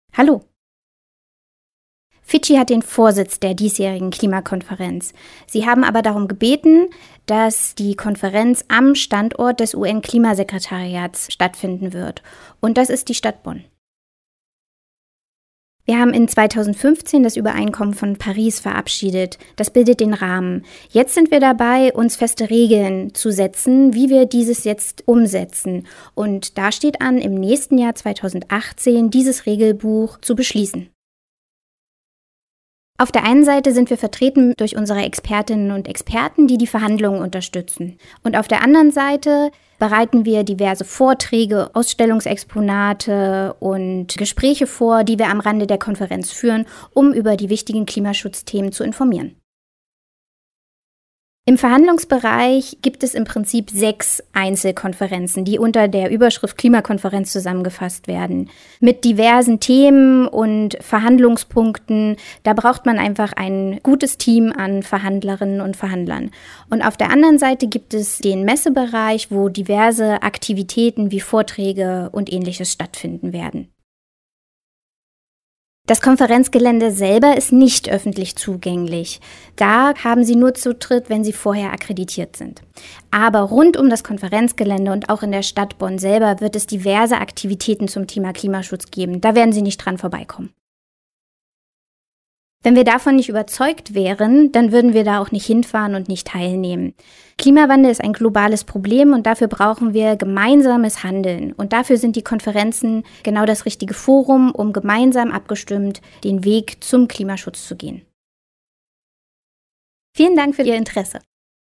Interview: 1:51 Minuten